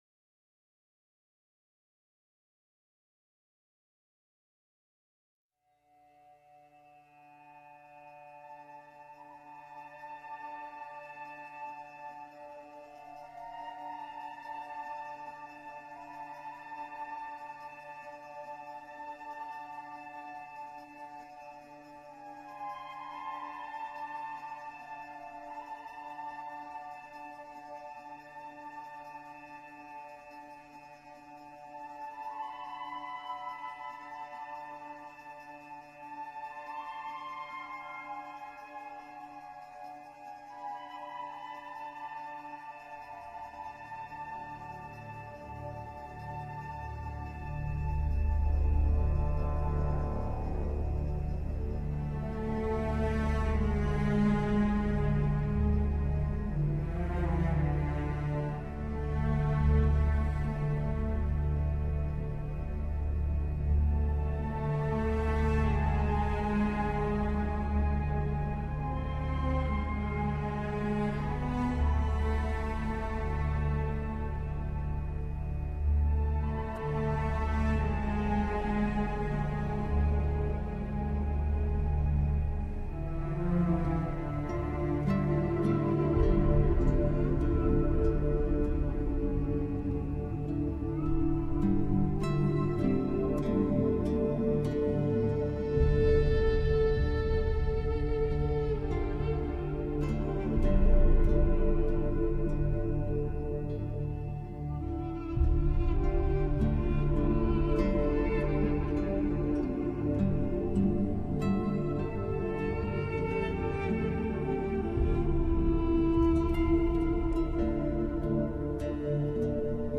موسیقی متن فیلم
Soundtrack, Score